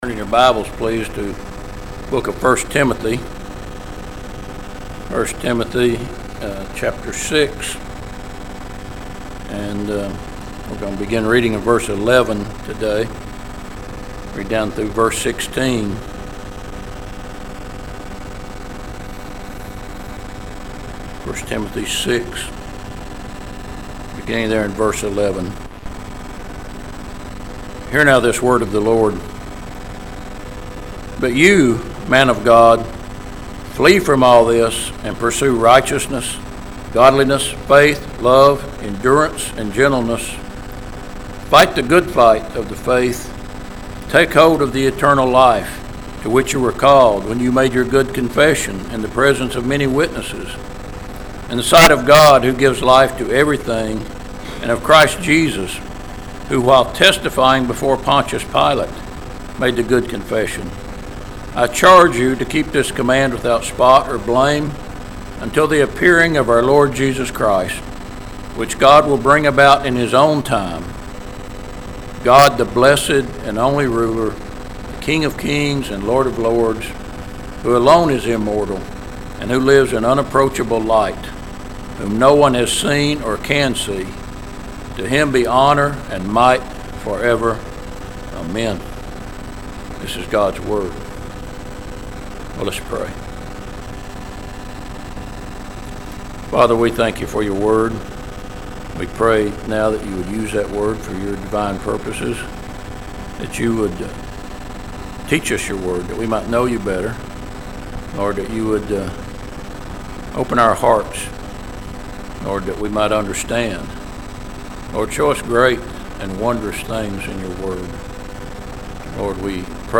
Click Here for 09/29/2024 Sermon